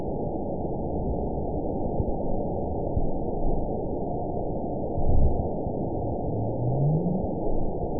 event 919855 date 01/26/24 time 01:41:20 GMT (1 year, 3 months ago) score 9.64 location TSS-AB03 detected by nrw target species NRW annotations +NRW Spectrogram: Frequency (kHz) vs. Time (s) audio not available .wav